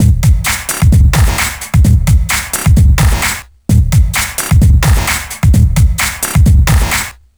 TSNRG2 Breakbeat 006.wav